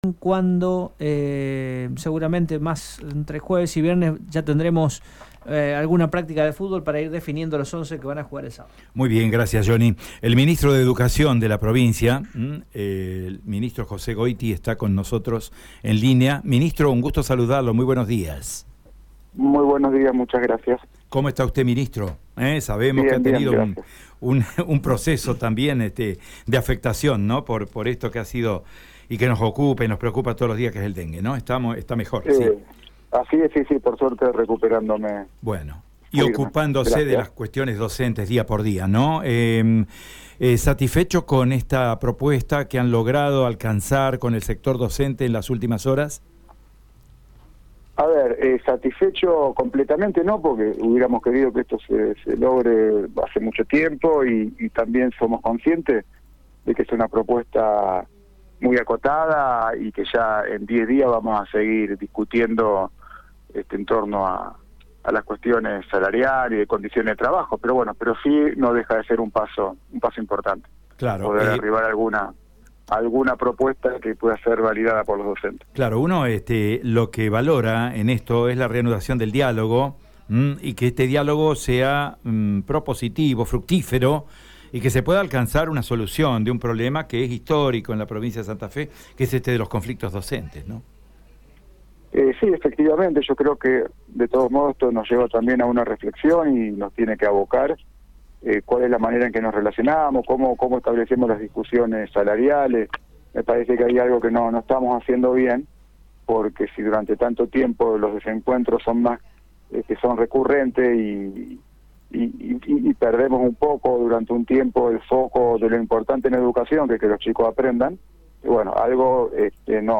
Ante esta situación, que los gremios no terminan de ver con buenos ojos, Radio EME dialogó con el Ministro de Educación de Santa Fe, José Goity.